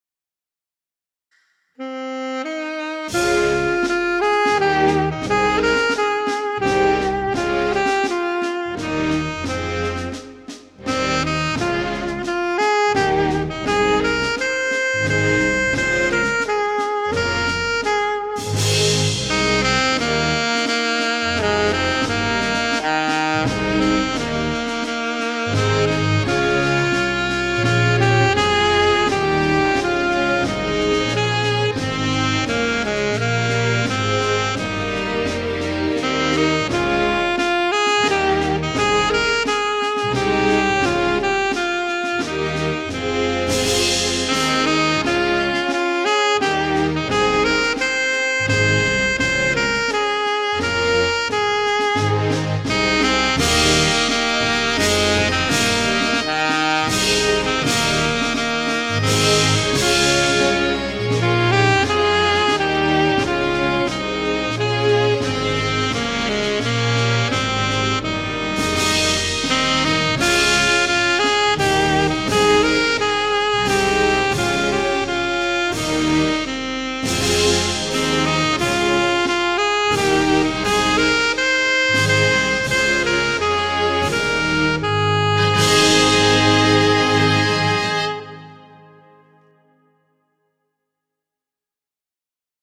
full orchestral backing tracks and performance tracks
Classical Music